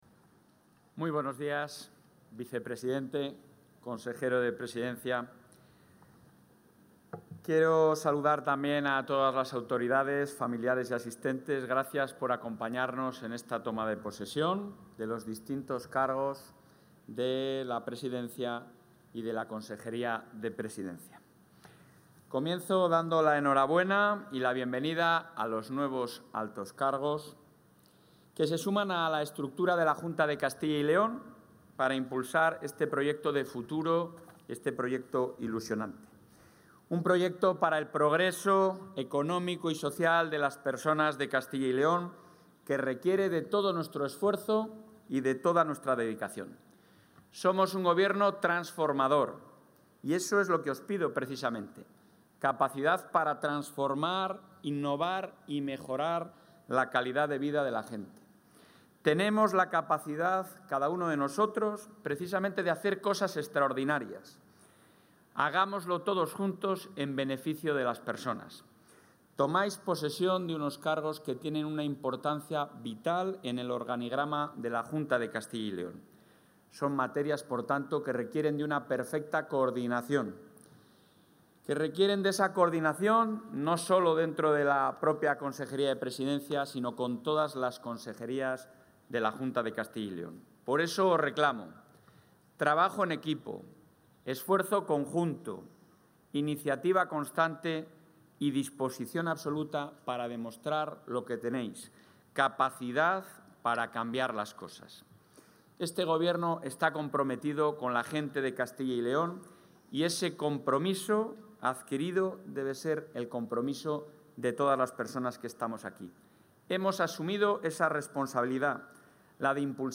Material audiovisual sobre el acto de toma de posesión de los nuevos altos cargos de la Consejería de la Presidencia
Intervención del presidente.